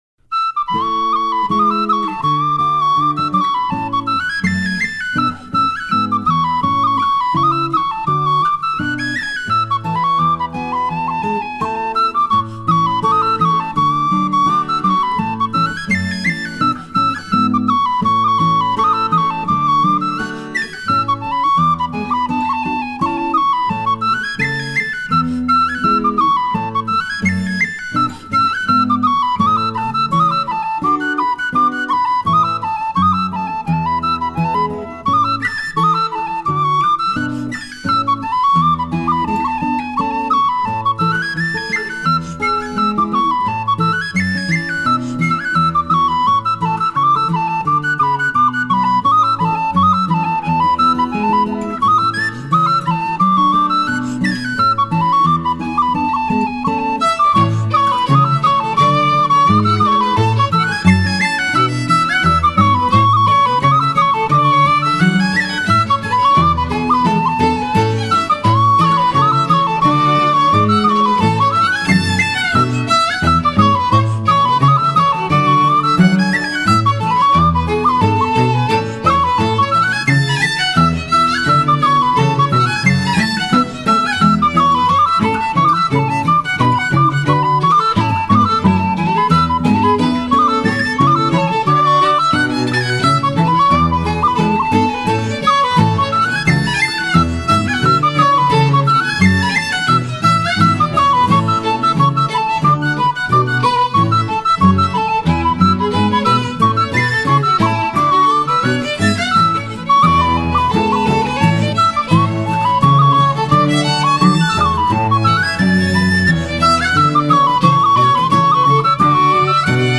流派：Folk
风笛倾诉着流浪和寂寞，流露出纯洁而洒脱的感情，干净而飘，苍凉而远，那声音纯净得不似来自人间。